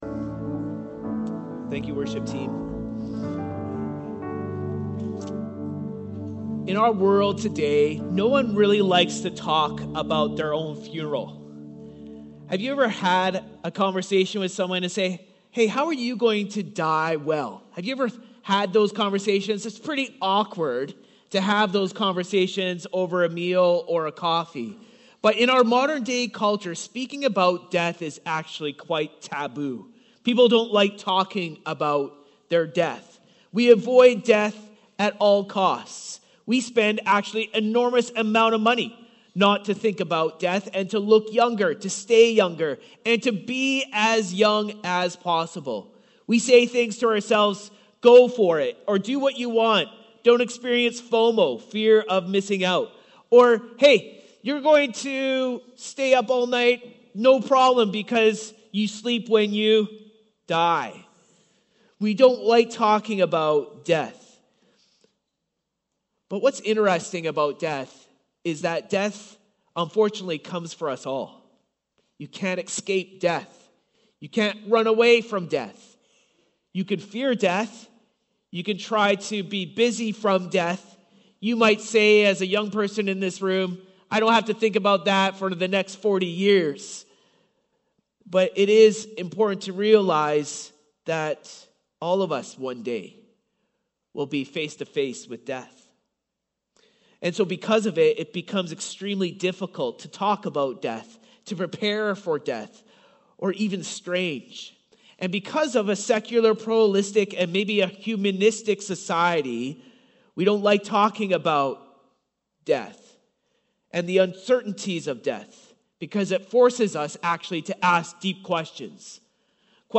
2026 Current Sermon Father